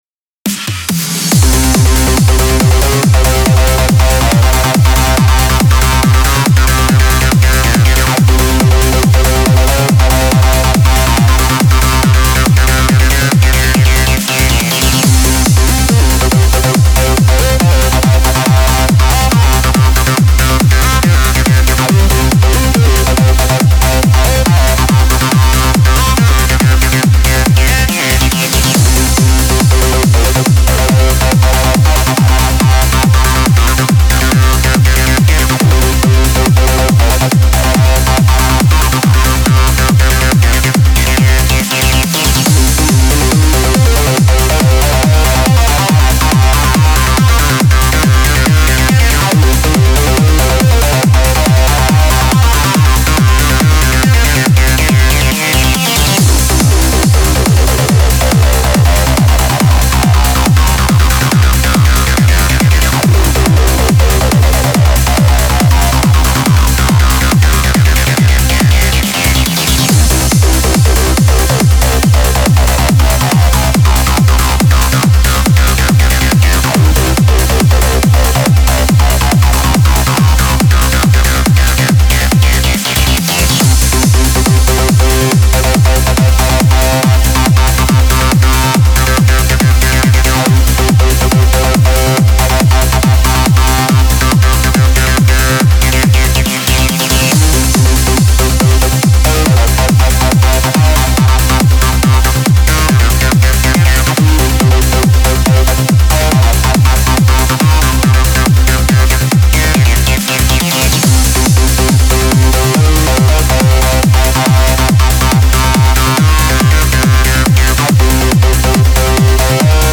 Melodic Techno Psy-Trance Techno Trance Uplifting Trance
Also included are the Spire preset you hear in the preview.
(Preview demo is 140 BPM)
Style: Trance, Uplifting Trance, Tech Trance, Techno